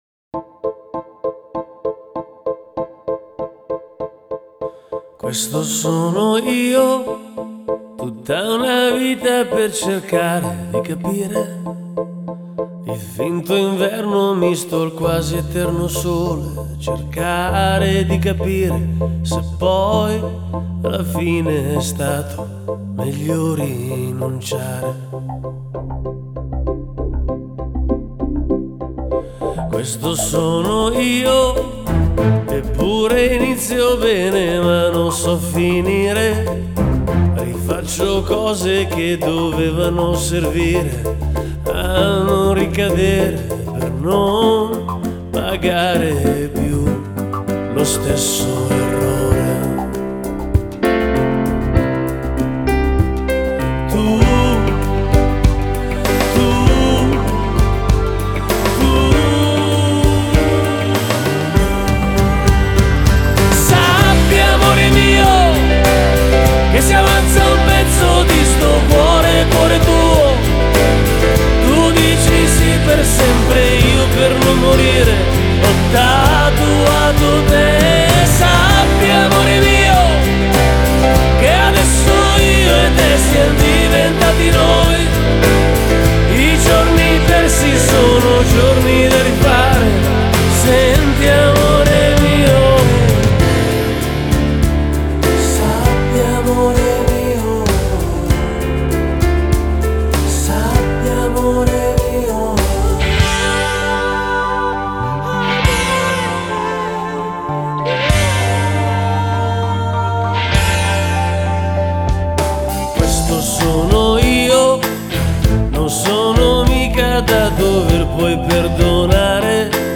Genre: Pop / Italia